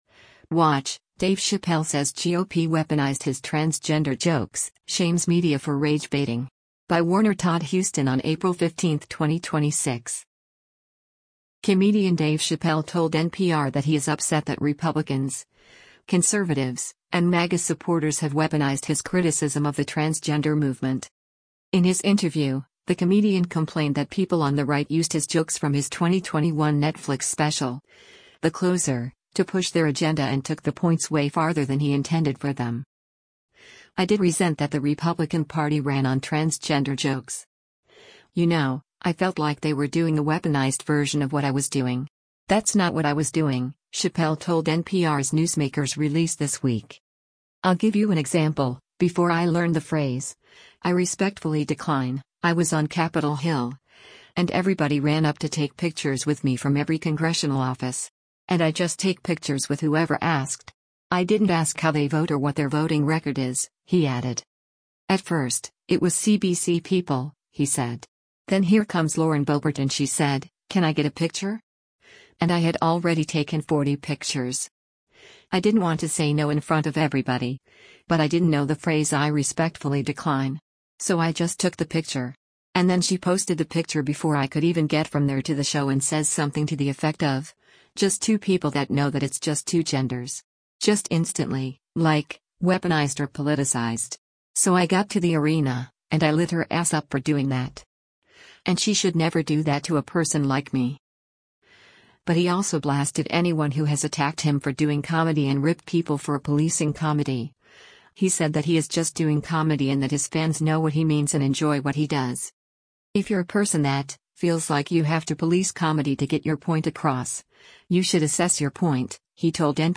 Comedian Dave Chappelle told NPR that he is upset that Republicans, conservatives, and MAGA supporters have “weaponized” his criticism of the transgender movement.